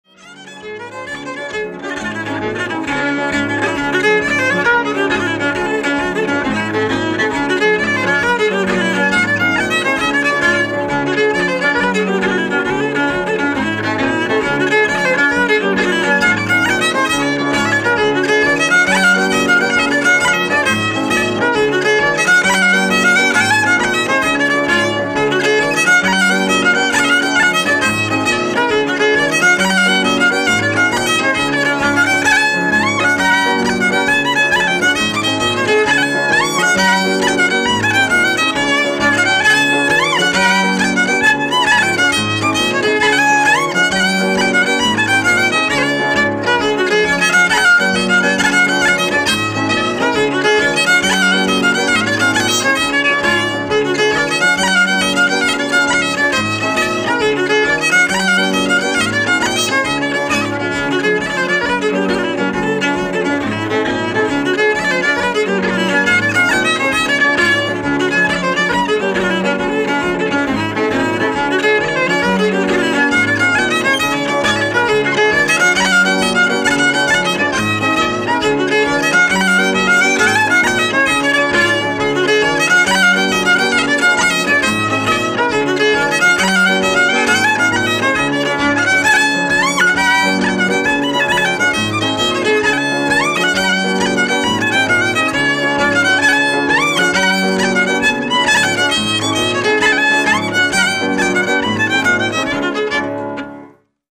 Girls of Banbridge ... Irish Jig
The Girls of Banbridge is a 19th century Irish jig which first appeared in O'Neill's Collection.